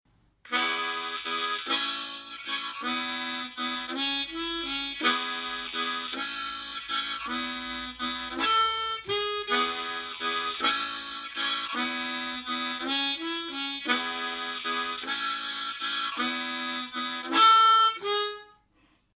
El ejercicio 7 es una mezcla de acuerdos y notas individuales.
Tiempo: 50 bpm, máximo 70 bpm. 4/4 – Harmonica en tonalidad: A
AHCOD - Audio_Exercise 7.mp3